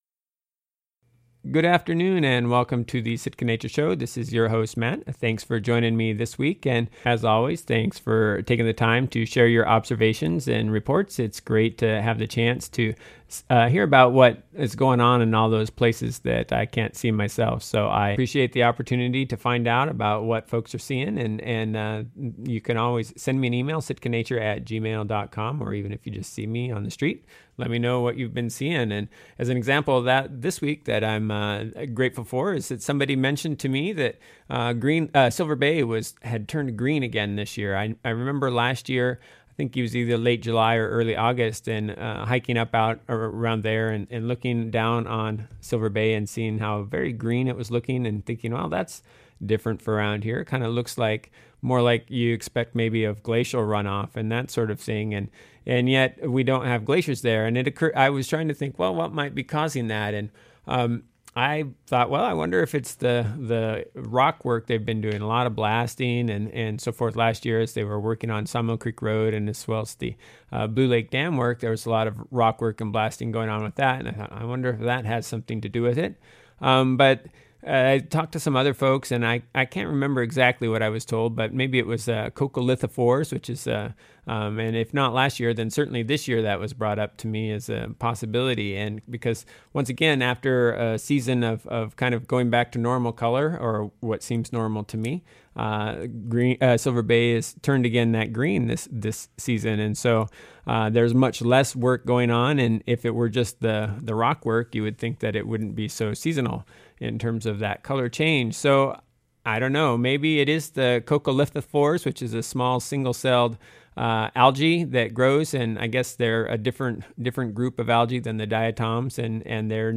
The 3 August show featured a conversation